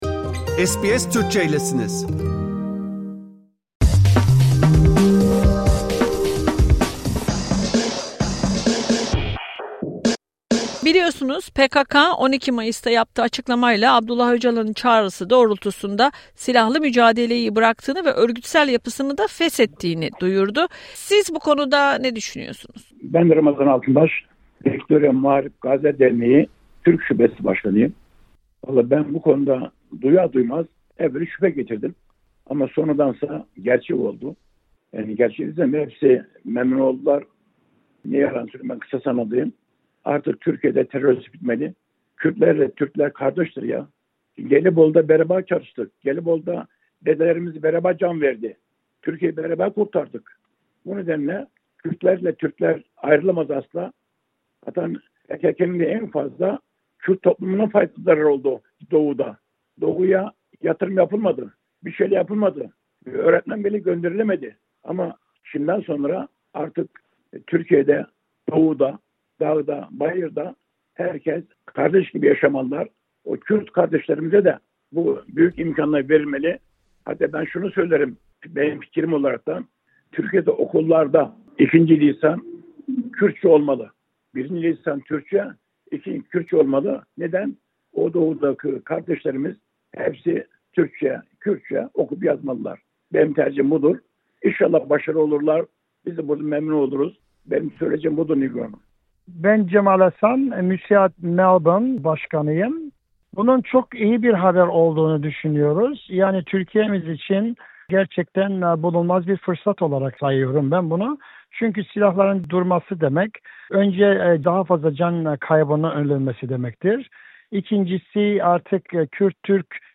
Peki Avustralya Türk toplumu, bu gelişmeye nasıl yaklaşıyor? Sydney ve Melbourne'dan toplum kuruluşlarının başkanlarını arayarak ne düşündüklerini sorduk.